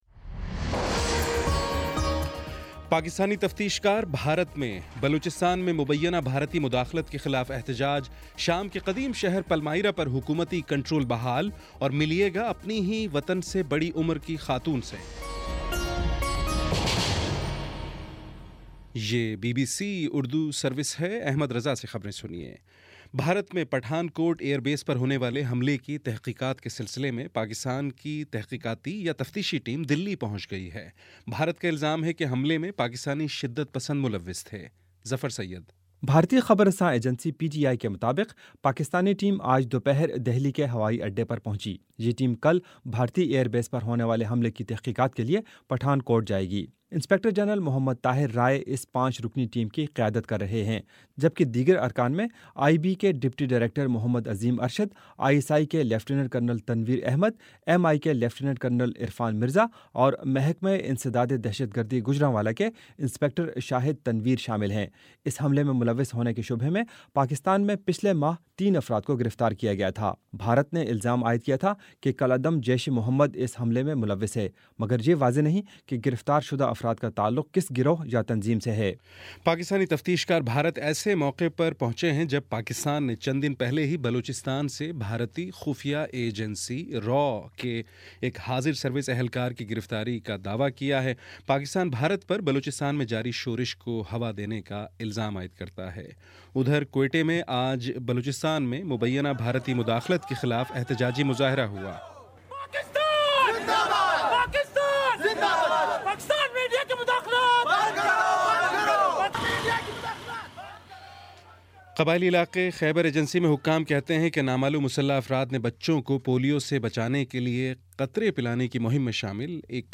مارچ 27 : شام پانچ بجے کا نیوز بُلیٹن